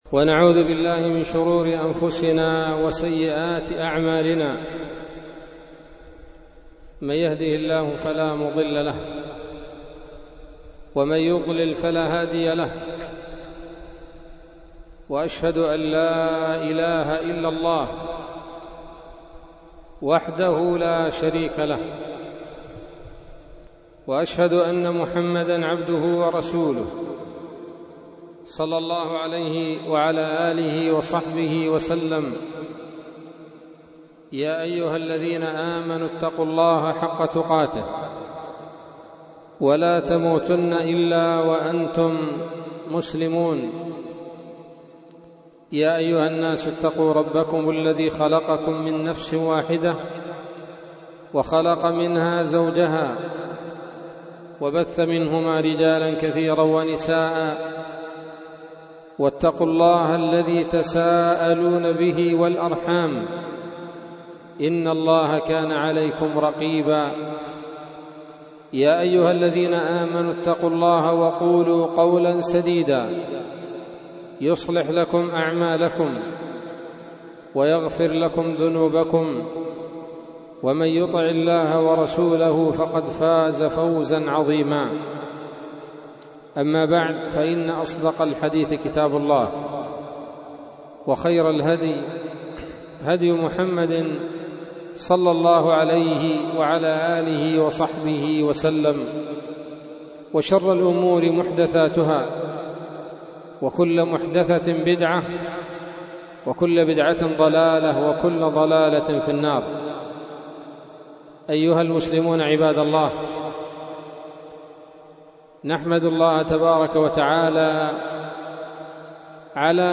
محاضرة بعنوان: (( الاستجابة لأمر الله ورسوله )) ليلة الخميس 4 من شهر ربيع الآخر لعام 1442 هـ، المسجد الكبير بصلاح الدين - عدن